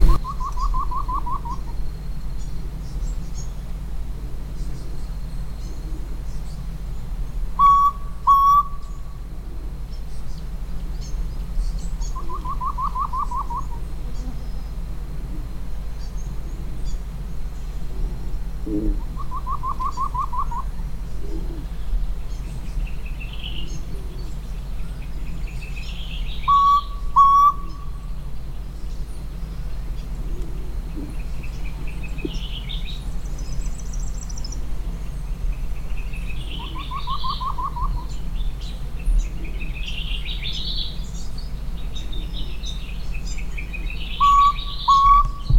Chululú Cabeza Rojiza (Grallaria albigula)
Nombre en inglés: White-throated Antpitta
Fase de la vida: Adulto
Provincia / Departamento: Salta
Condición: Silvestre
Certeza: Observada, Vocalización Grabada